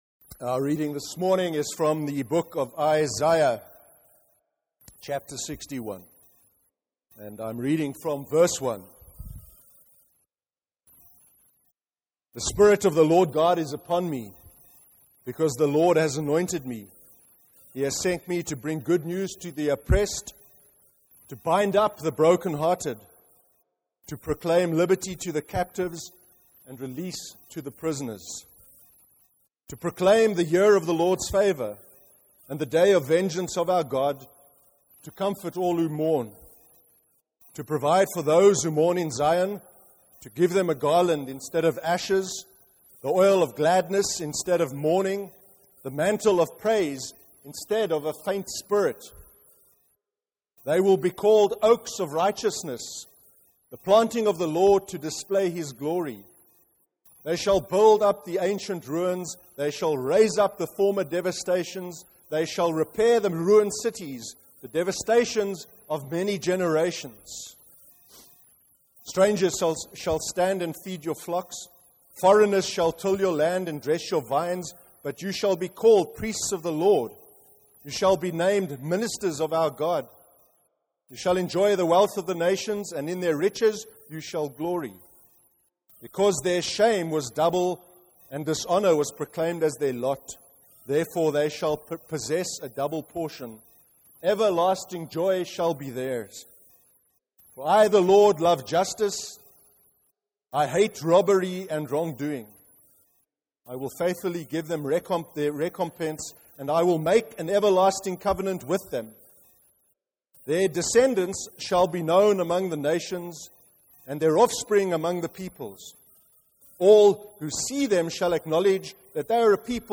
14/12/2014 sermon – God’s Grace (Isaiah 61)